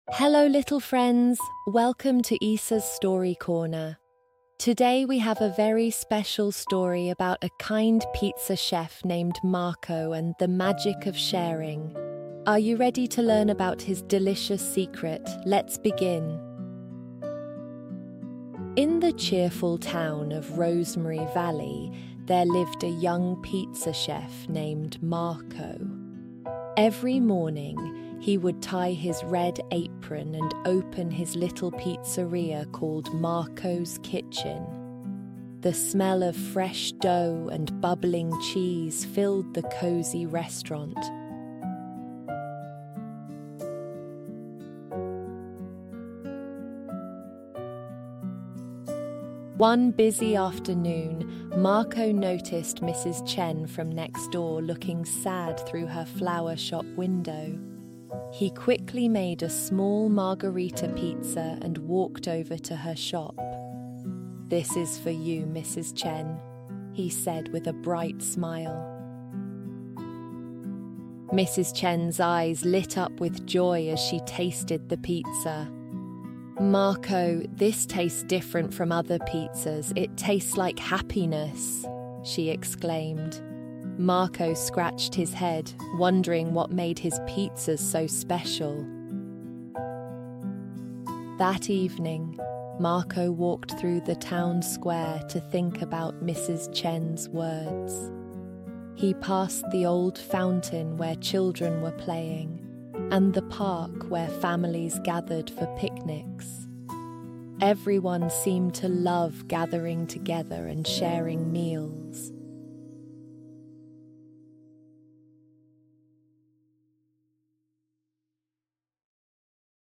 Cuento en Audio